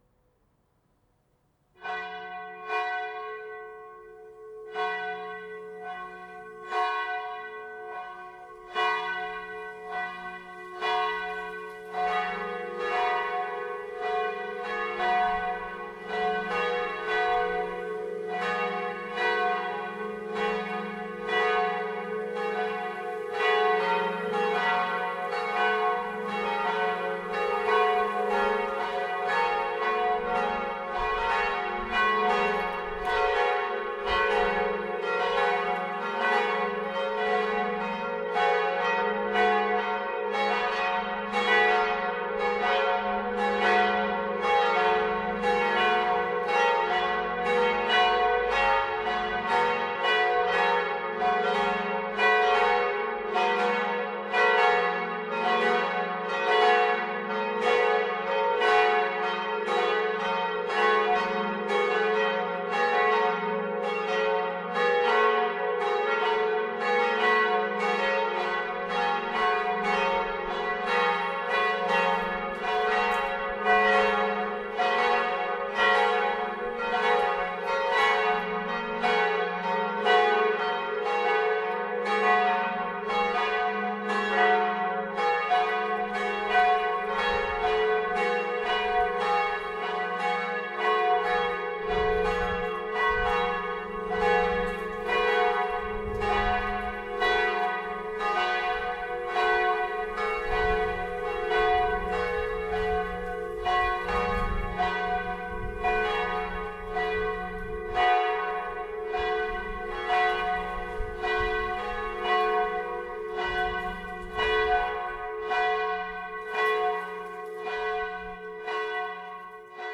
Unsere drei Glocken
Hier läuten unsere Glocken
Die größte auf 'ges' gestimmte Glocke ist 690 kg schwer und trägt die Inschrift:
Die mittlere auf 'as' gestimmte Glocke wiegt 490 kg und hat heute die Inschrift:
Glocken_Ev._Kirche_Floersheim.mp3